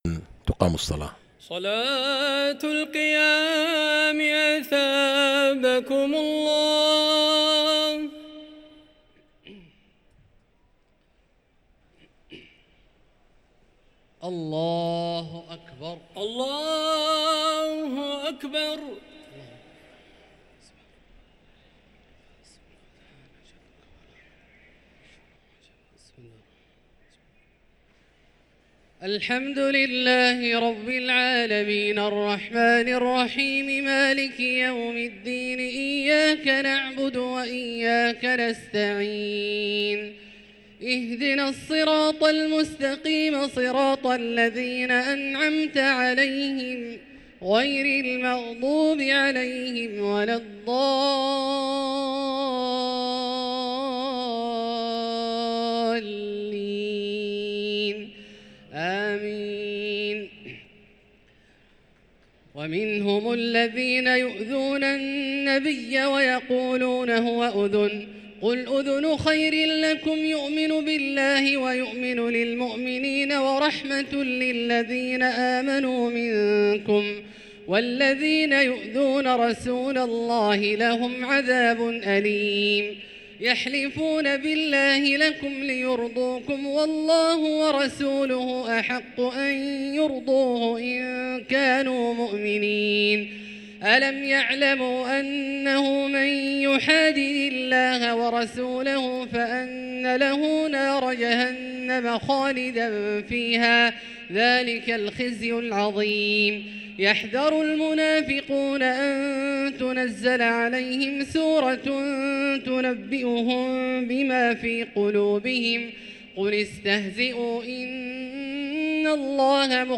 صلاة التراويح ليلة 14 رمضان 1444 للقارئ عبدالله الجهني - الثلاث التسليمات الأولى صلاة التراويح